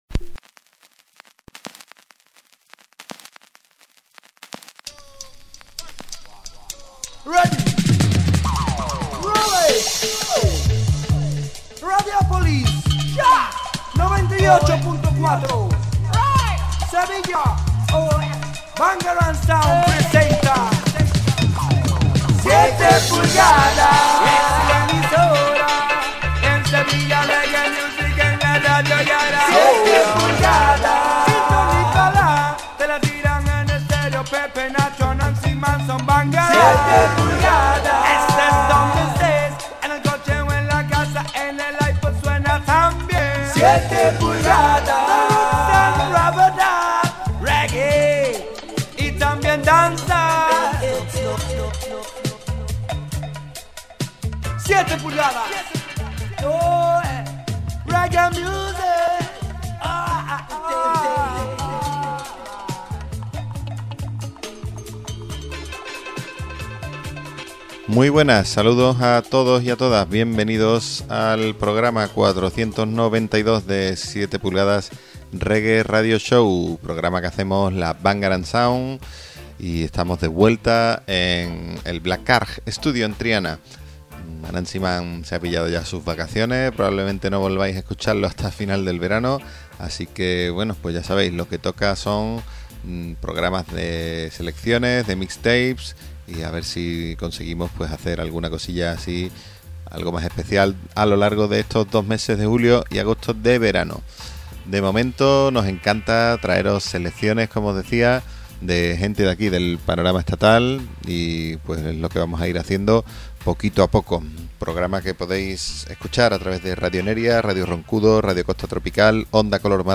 reggae y dancehall
una selección llena de energía